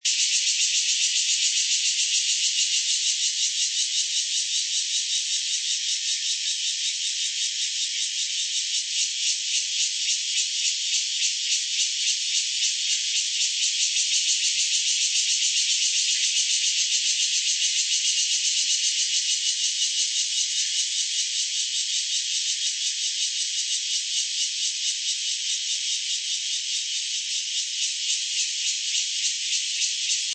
澄清湖高砂熊蟬1.mp3
高砂熊蟬 Cryptotympana takasagona
錄音地點 高雄市 鳥松區 澄清湖
錄音環境 雜木林
雄高砂熊蟬呼喚歌聲合唱